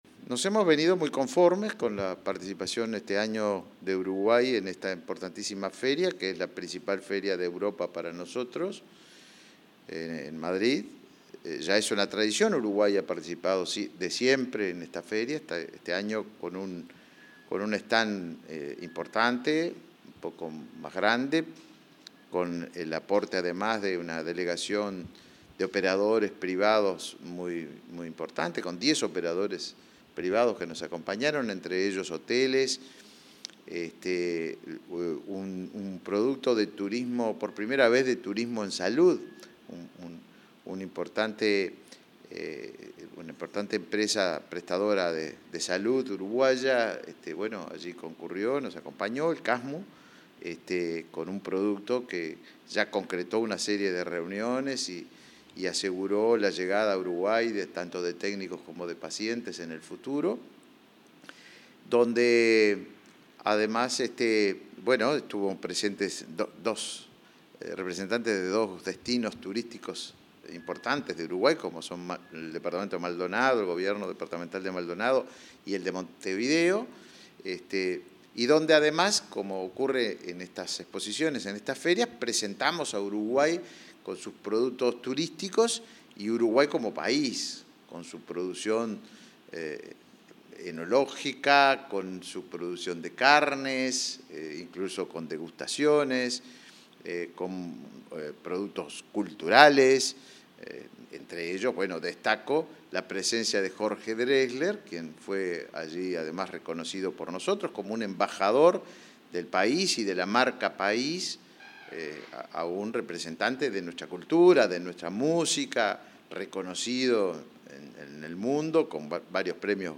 Entrevista al ministro de Turismo, Tabaré Viera